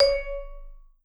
Index of /90_sSampleCDs/Sampleheads - Dave Samuels Marimba & Vibes/VIBE CMB 2C